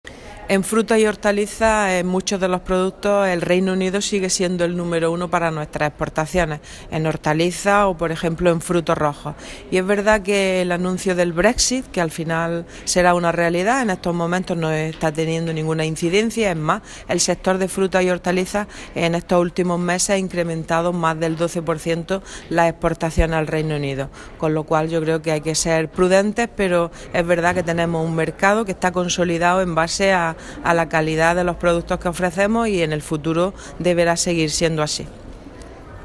Declaraciones consejera Brexit